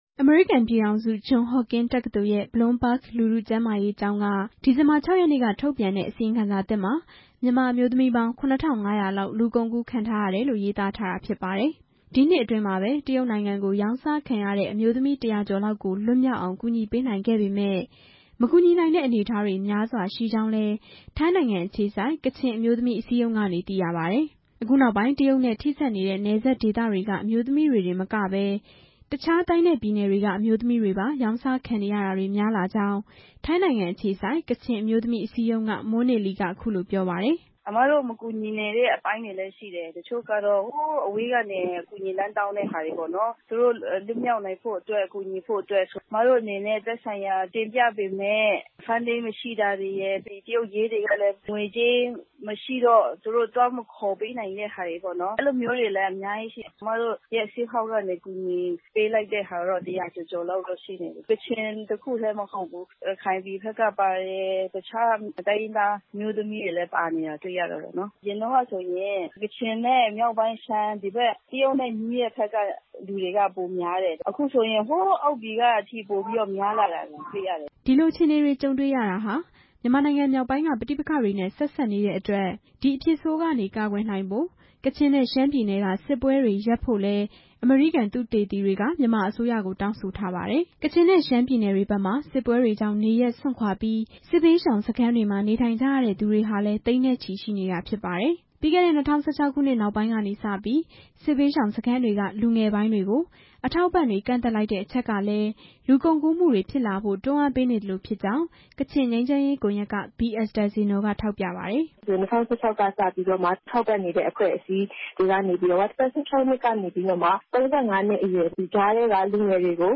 ဒီကိစ္စနဲ့ပတ်သက်လို့ အမျိုးသမီးအရေးလှုပ်ရှားသူတွေကို ဆက်သွယ်မေးမြန်းပြီး